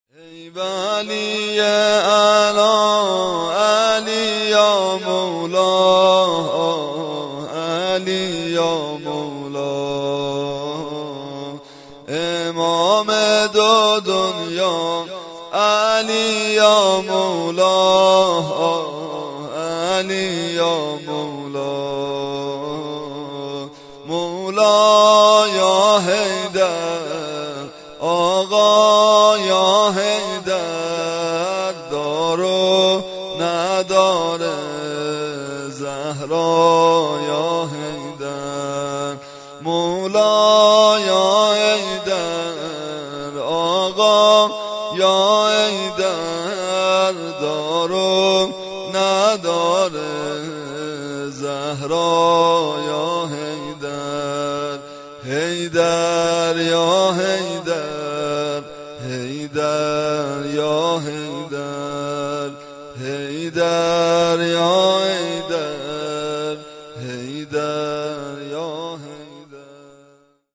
دانلود سبک و متن نوحه واحد و شور شب بیست و یکم ماه رمضان -( ای ولیّ اعلا،علی یا مولا آه علی یا مولا )